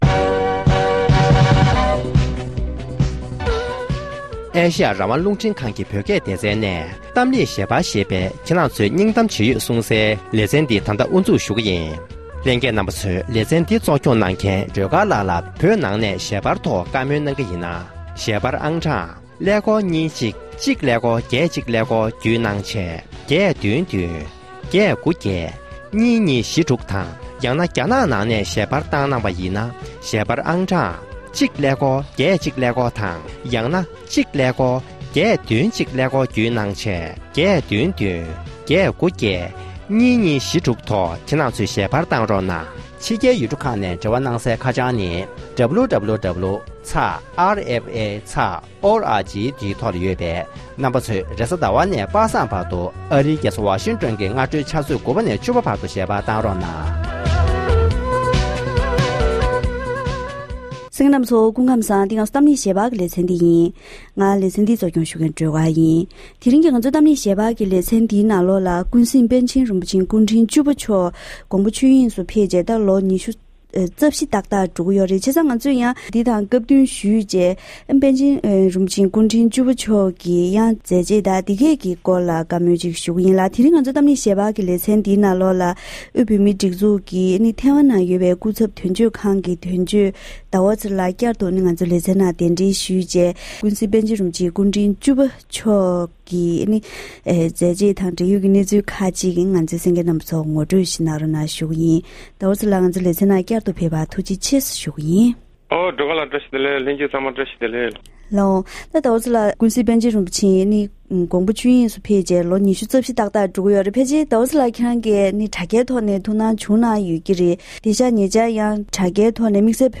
༄༅༎དེ་རིང་གི་གཏམ་གླེང་ཞལ་པར་ལེ་ཚན་ནང་ཀུན་གཟིགས་པཎ་ཆེན་རིན་པོ་ཆེ་སྐུ་ཕྲེང་བཅུ་པ་ཆོས་ཀྱི་རྒྱལ་མཚན་མཆོག་དགོངས་པ་ཆོས་དབྱིངས་སུ་ཕེབས་ནས་ལོ་ངོ་༢༤འཁོར་བའི་སྐབས་དང་བསྟུན་ནས་པཎ་ཆེན་རིན་པོ་ཆེ་སྐུ་ཕྲེང་བཅུ་པའི་མཛད་རྗེས་སྐོར་ལ་ཐེ་ཝན་དོན་གཅོད་ཟླ་བ་ཚེ་རིང་ལགས་དང་ལྷན་བཀའ་མོལ་ཞུས་པ་ཞིག་གསན་རོགས་གནང་།།